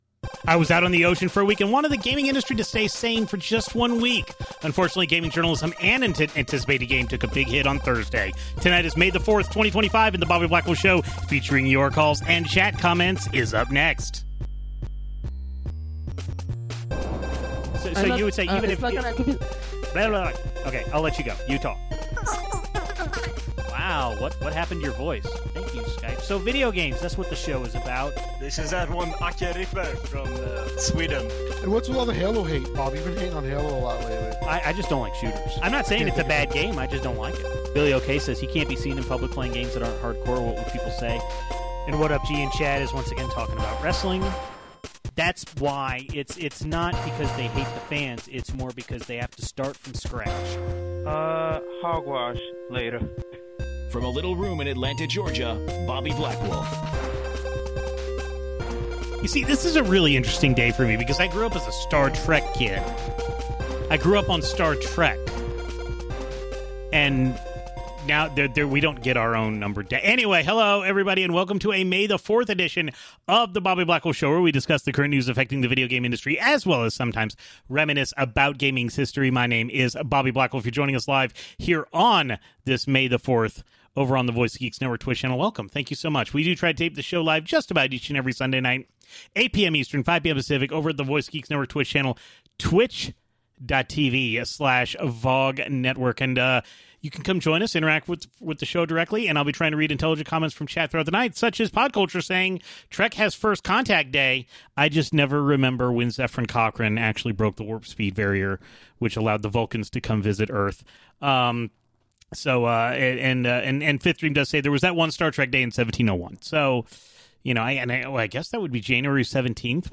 Fandom abruptly shut down all Giant Bomb livestreaming after sending new brand guidelines making everything PG, which caused some influential people to leave the outlet. Unfortunately, my DNS servers went down half way through the show so the show is a little bit shorter and disjointed.